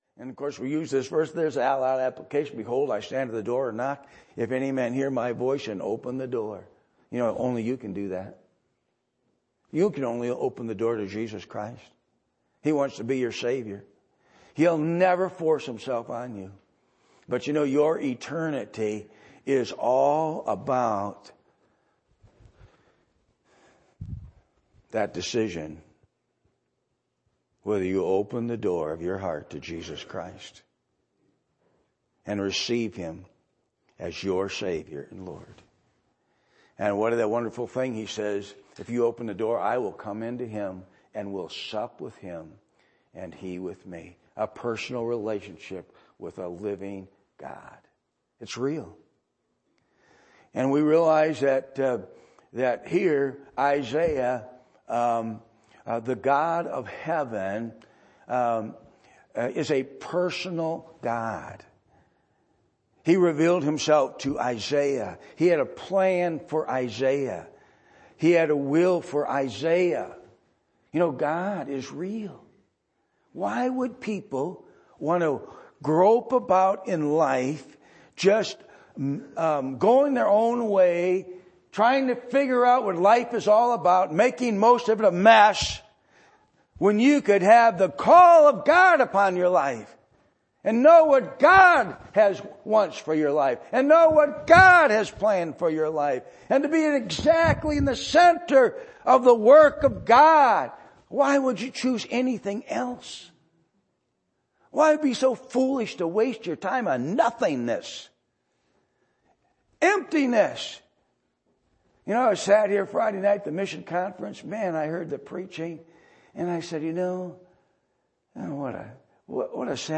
Passage: Isaiah 6:1-8 Service Type: Sunday Morning